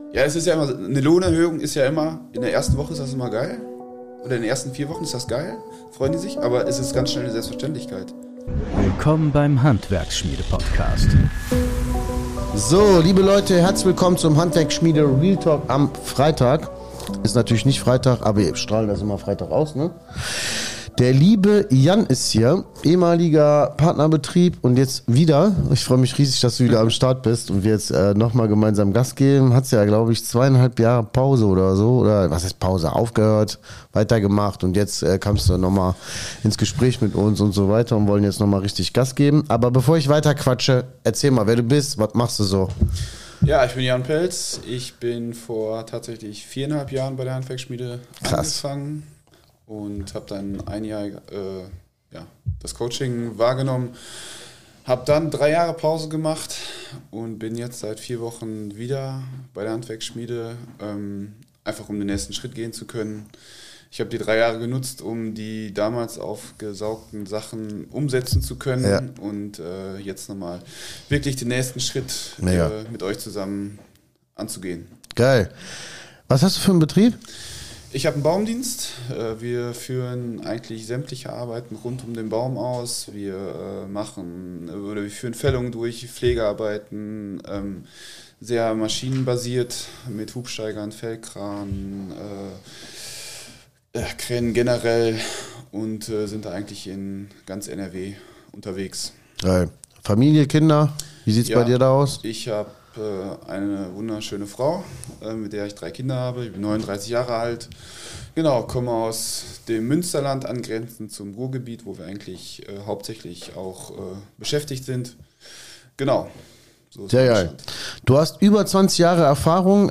Ein ehrliches Gespräch aus der Praxis – ohne Schönreden, ohne Blabla.